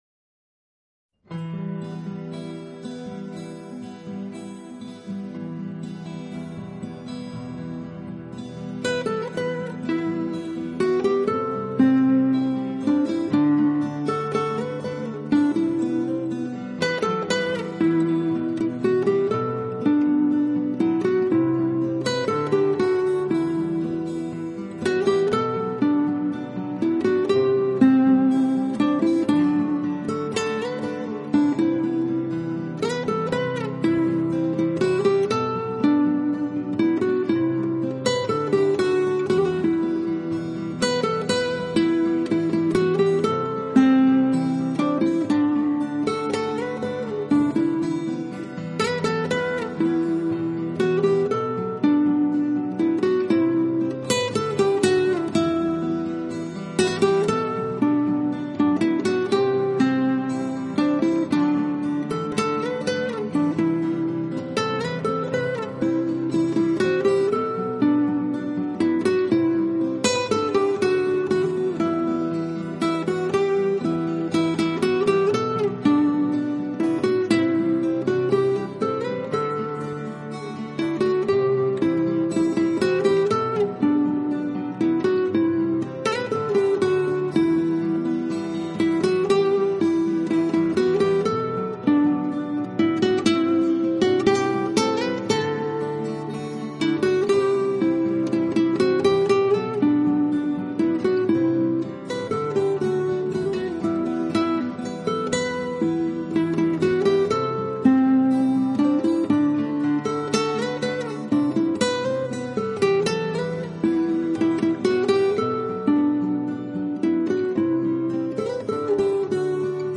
音乐类别：纯音乐